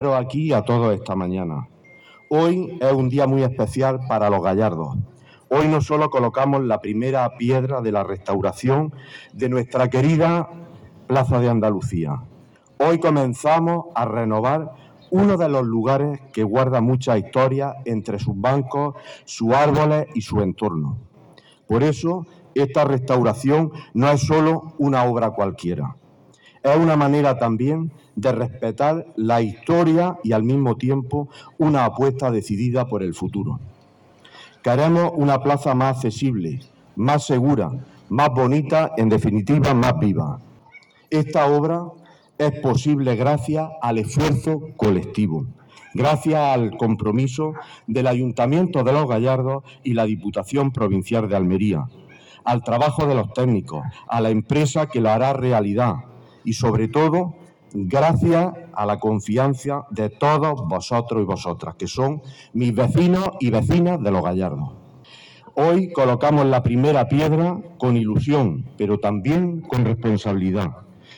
24-02_los_gallardos_alcalde.mp3.mp3